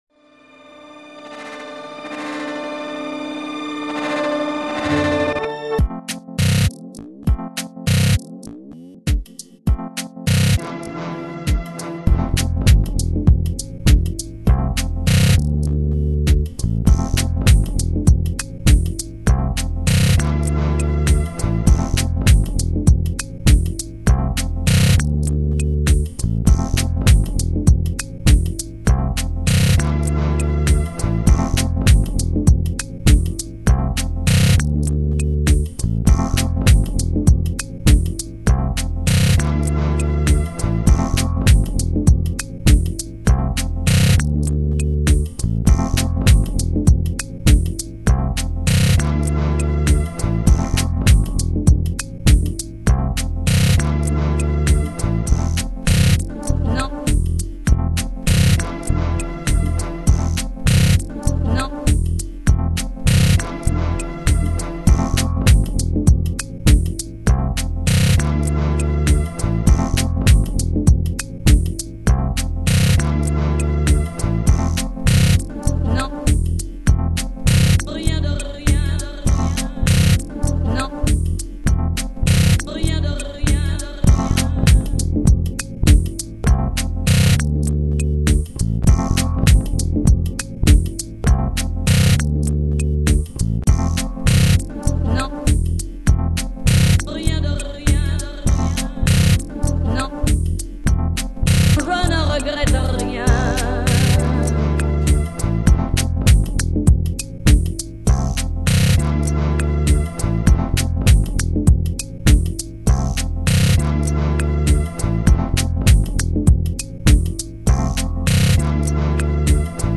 Downbeat
bass